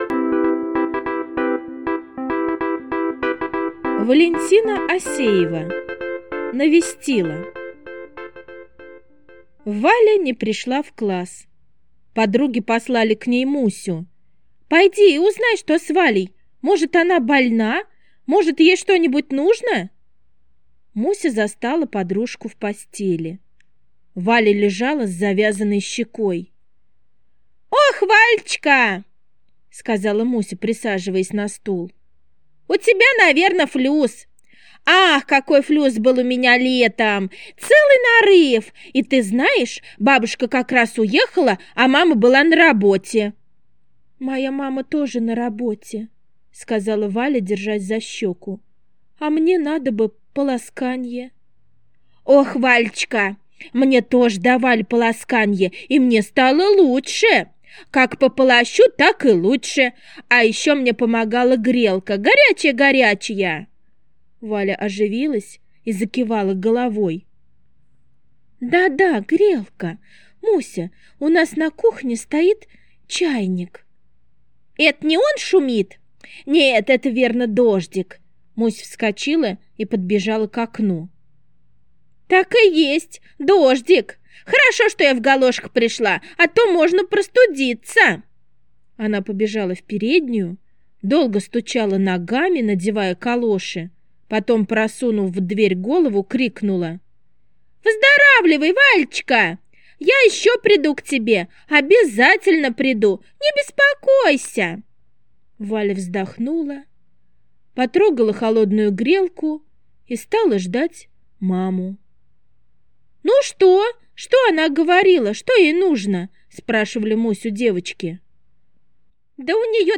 Навестила - аудио рассказ Осеевой В. Рассказ про девочку Мусю, которая ходила навестить больную одноклассницу...